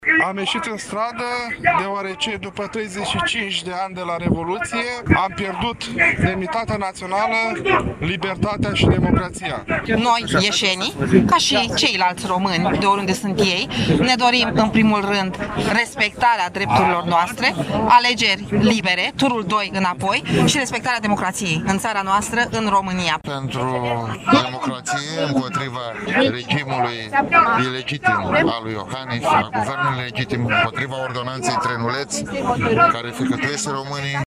Și la Iași, aproximativ 500 de persoane au participat la un miting și la un marș de protest prin care au cerut reinstaurarea democrației.
18-ian-ora-16-Vox-Protest-Iasi.mp3